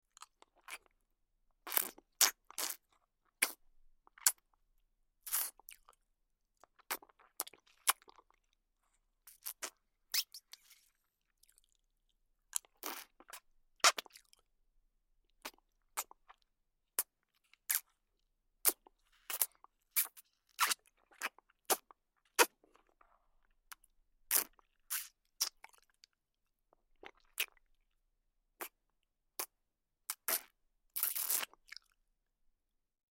Звуки сосания груди
Тихо сосет